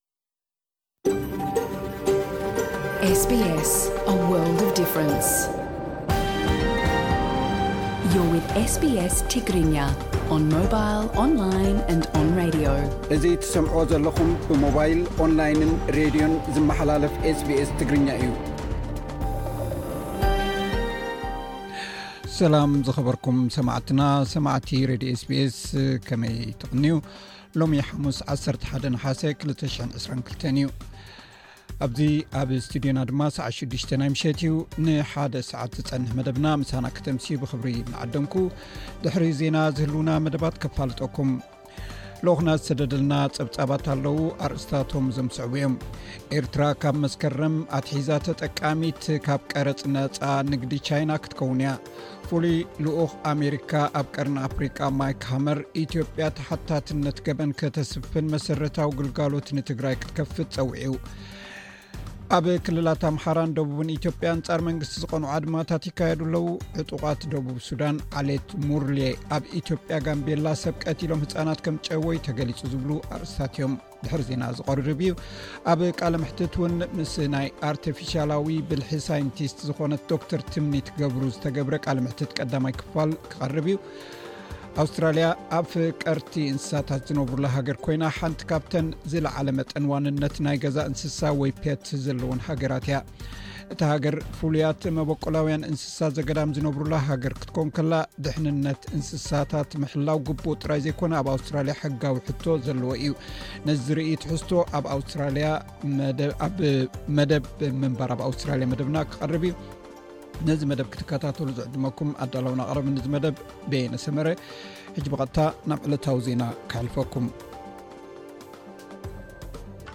ሎሚ ሓሙስ 11 ነሓሰ 2022 ብናይ ኣቆጻጽራ ሰዓት ስቱድዮና ስቱድዮ መልበርን አውስትራሊያ ልክዕ ሰዓት 6 ናይ ሚሸት እዩ፡ ናይ ሓደ ሰዓት መደብና ፡ ዕለታዊ ዜና፡ ጸብጻብ፥ ኣብ ቃለ መሕትት፥ ምስ ናይ ኣርተፊሻላዊ ብልሒ ሳይንቲስት ዝኾነት ዶ/ር ትምኒት ገብሩ ዝተገብረ ቃለ መሕትት ቀዳማይ ክፋል ክቐርብዩ። ኣውስትራልያ ፡ ድሕንነት እንስሳት ምሕላው ግቡእ ጥራይ ዘይኮነ ኣብ ኣውስትራሊያ ሕጋዊ ሕቶ ዘለዎ እዩ ።ነዚ ዝርኢ ትሕዝቶ ኣብ ምንባር ኣውስትራሊያ መደብ ኣለና፡ ክቐርብ እዩ። ካልእን